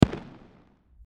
Fireworks #3 | TLIU Studios
Category: Explosions Mood: Festive Editor's Choice